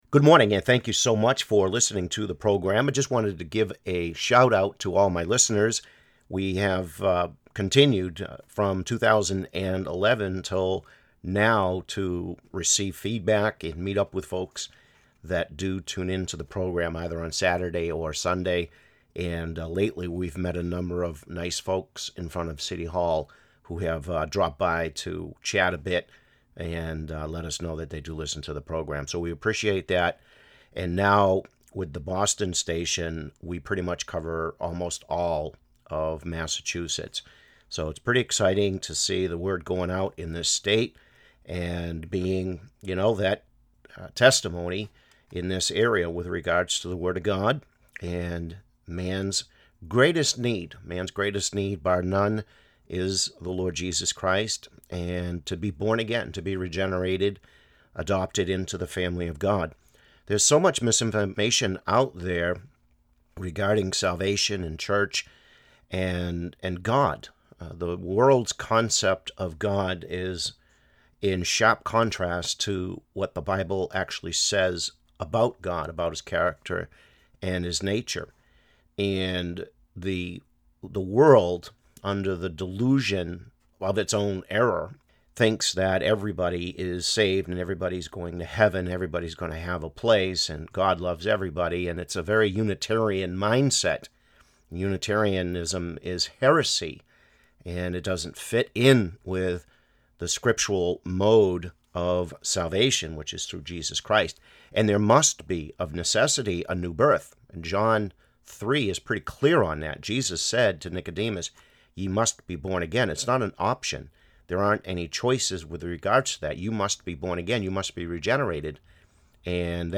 Just a snippet from our on going study in Systematic Theology in adult Sunday School